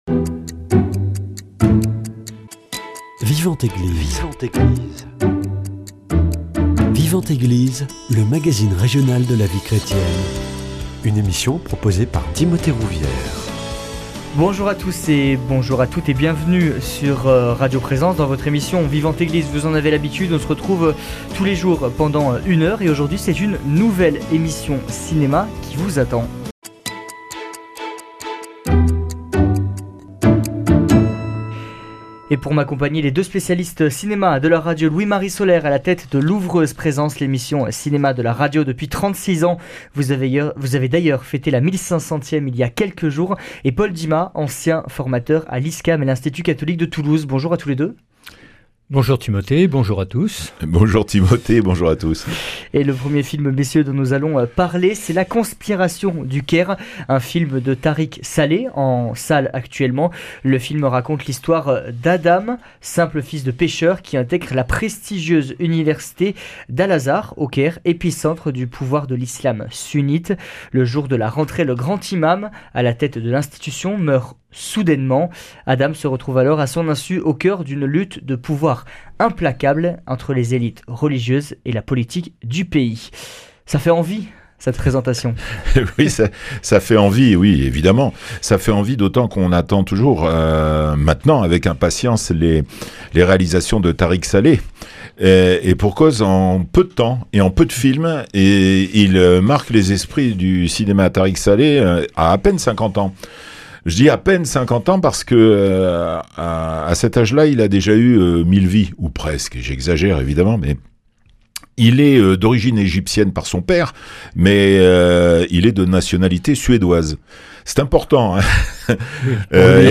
Nouvelle émission cinéma dans votre émission Vivante Eglise.